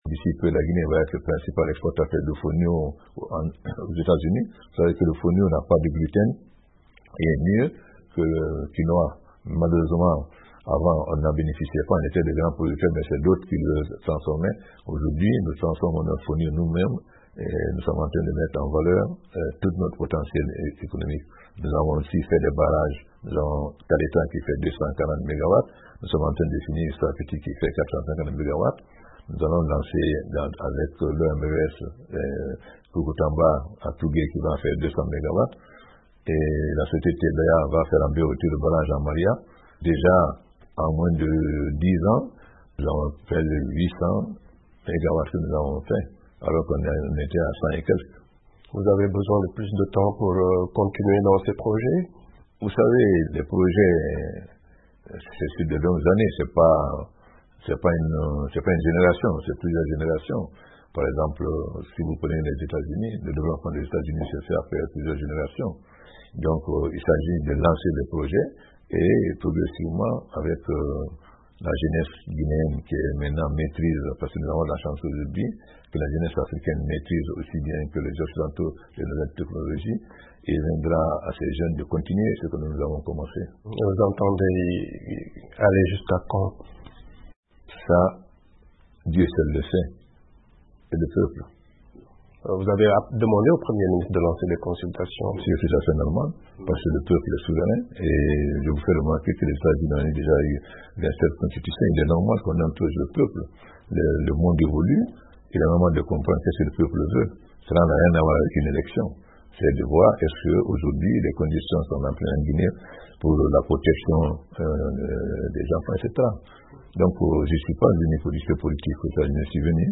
Dans un entretien exclusif à VOA Afrique, le président Alpha Condé évoque aussi les raisons de son récent appel à des consultations en vue de changer la constitution de son pays.